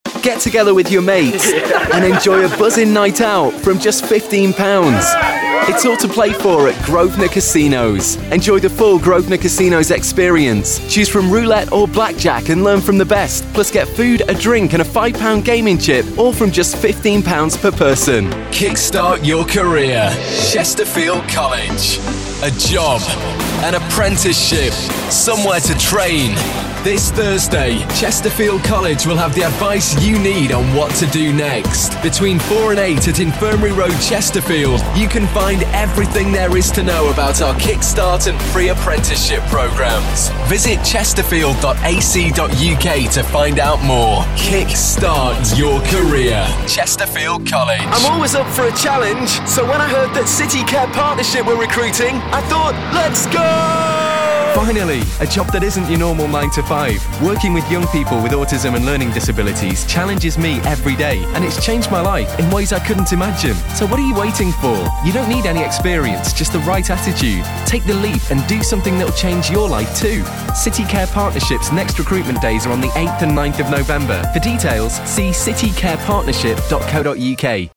Hello! I am a professional English voice talent with a neutral or northern UK accent.